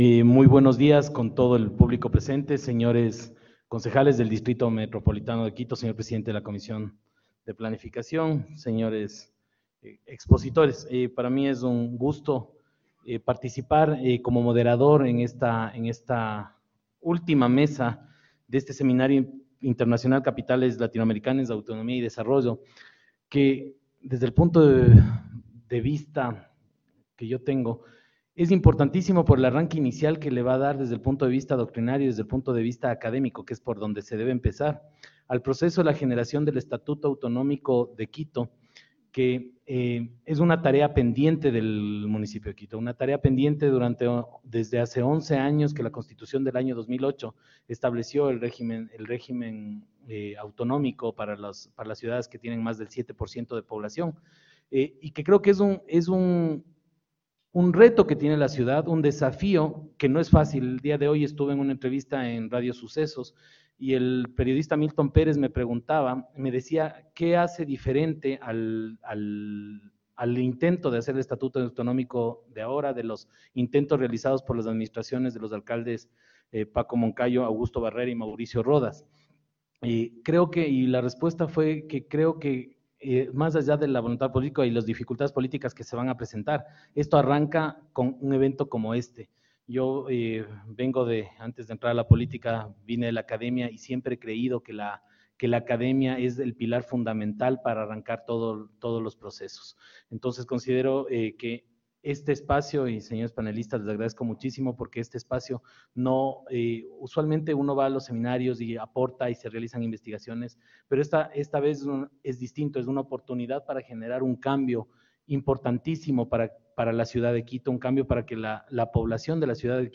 Seminario Internacional: Capitales latinoamericanas: autonomía y desarrollo, 22 y 23 de agosto de 2019. Hemiciclo FLACSO Ecuador.
Moderador: René Bedón, concejal del MDMQ.